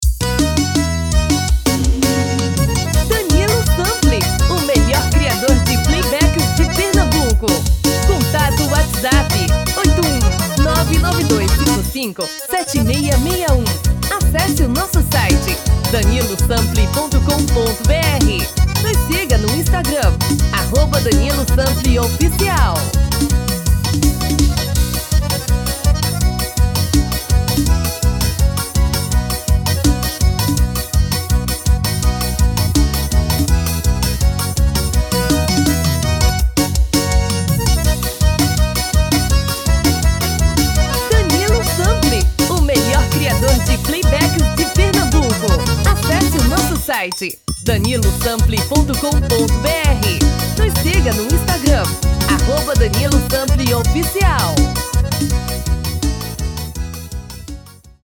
DEMO 1: tom original / DEMO 2: tom feminino